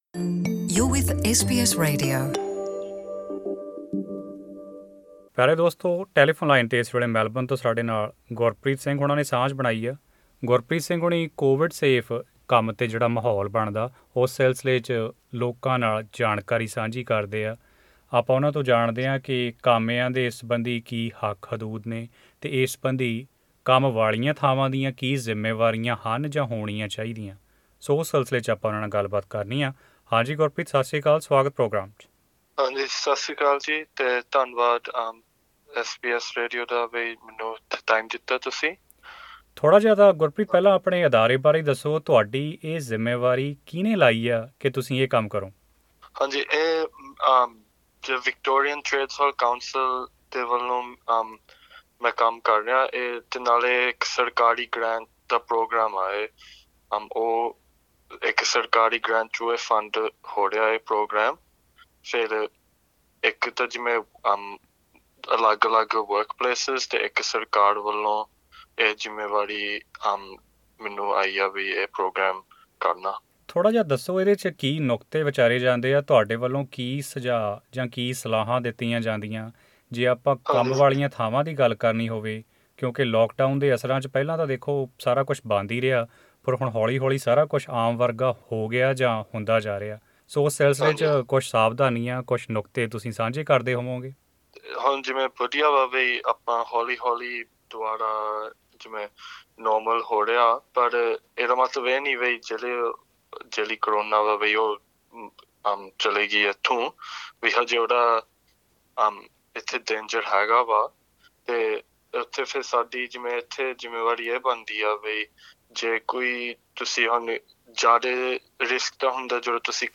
ਕਰਮਚਾਰੀਆਂ ਦੁਆਰਾ ਆਮ ਤੌਰ ਉੱਤੇ ਉਠਾਈਆਂ ਜਾਂਦੀਆਂ ਸੁੱਰਖਿਆ ਚਿੰਤਾਵਾਂ ਬਾਰੇ ਜਾਨਣ ਲਈ ਇਹ ਆਡੀਓ ਇੰਟਰਵਿਊ ਸੁਣੋ।